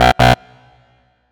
Failed.mp3